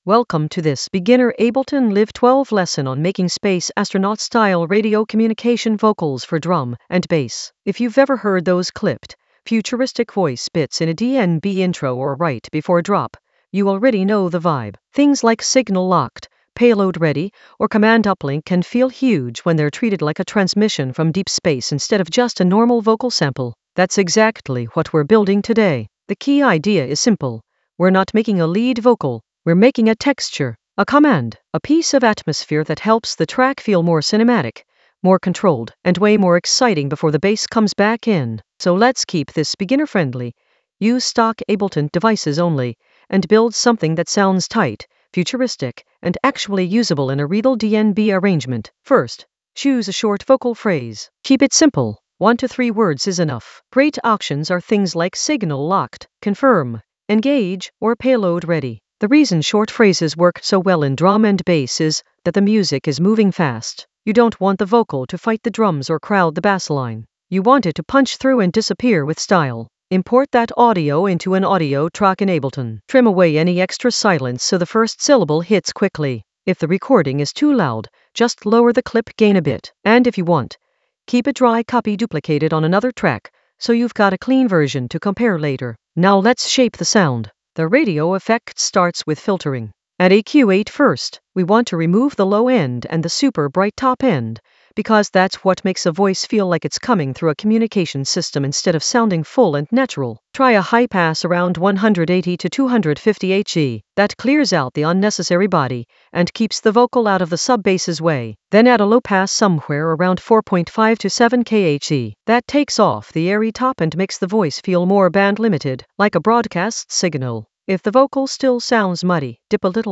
An AI-generated beginner Ableton lesson focused on Make Space Astronaut style radio communication vocals sound effects in Ableton Live 12 in the Basslines area of drum and bass production.
Narrated lesson audio
The voice track includes the tutorial plus extra teacher commentary.